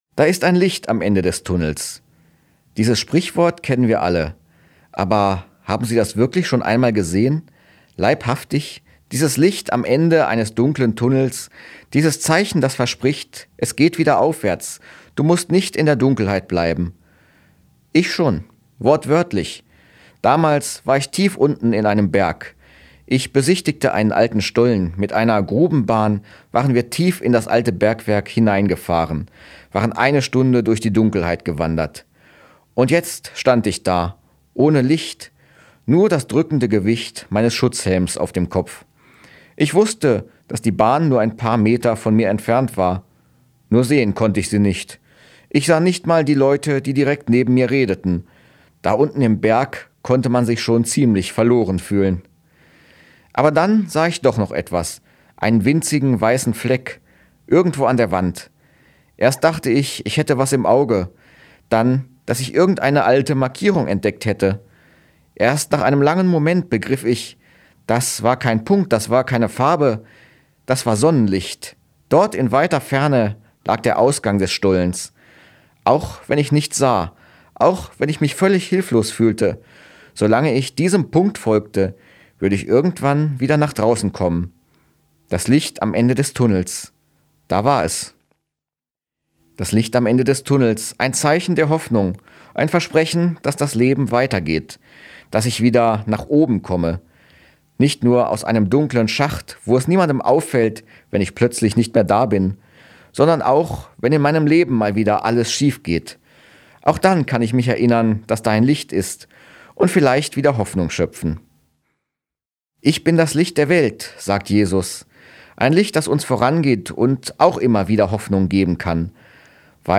Radioandacht vom 3. Mai